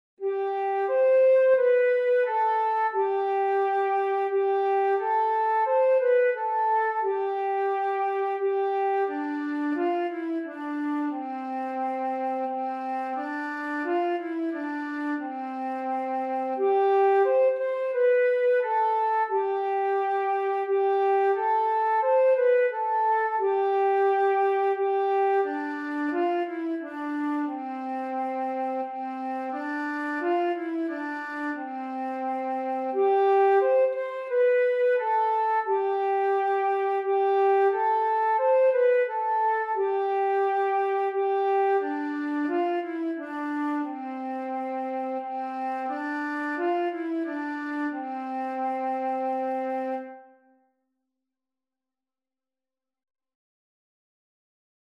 In höherer- und tiefer Tonart
Langsam, feierlich